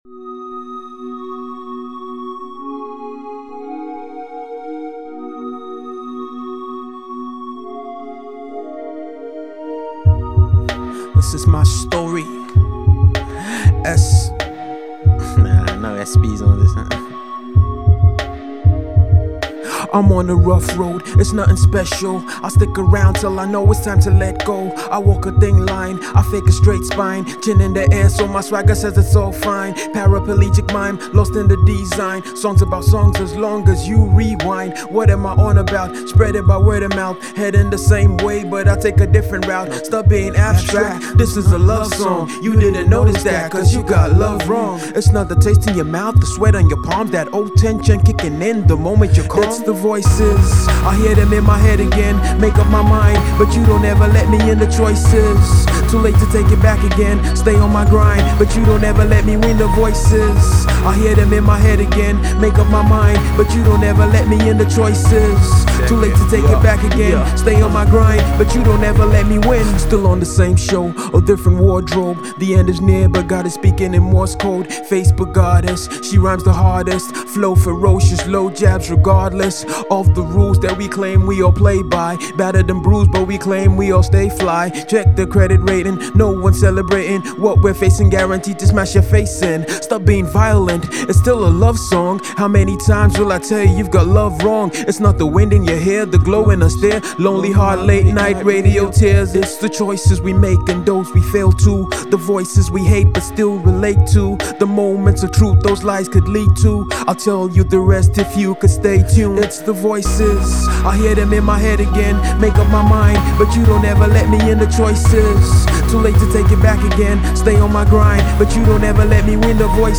Note*recommended for Hip-Hop Heads…